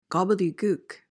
PRONUNCIATION:
(GOB-uhl-dee-gook)